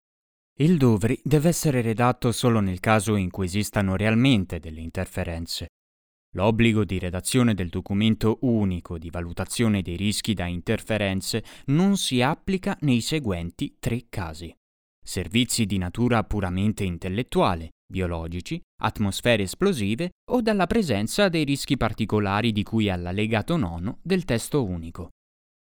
Dubber, speaker, italian voiceover, commercials, corporate, E-learning, animations, jingle, singer, games, youtube videos, documentaries,
Sprechprobe: eLearning (Muttersprache):
E-Learning DUVRI.mp3